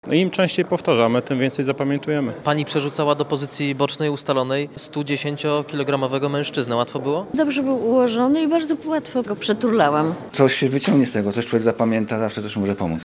Czy wiedza dotycząca ochrony i obrony cywilnej jest przydatna? Z uczestnikami szkolenia rozmawiał nasz reporter.